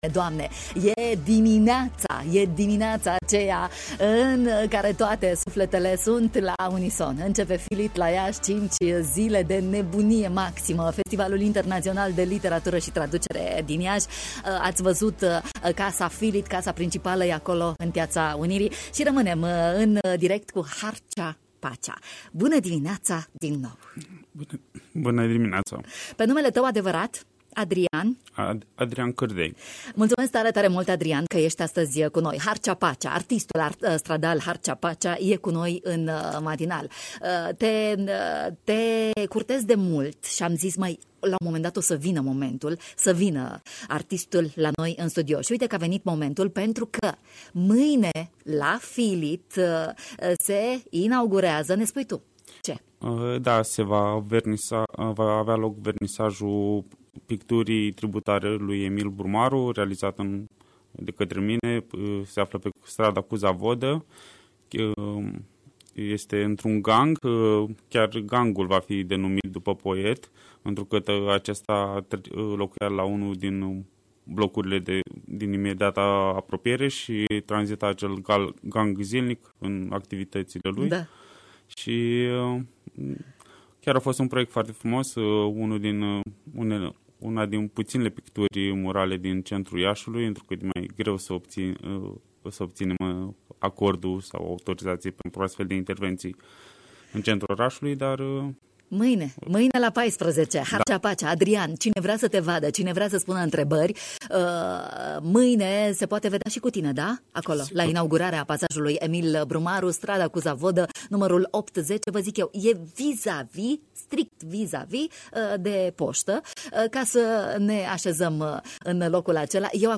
în direct la microfonul Radio România Iași: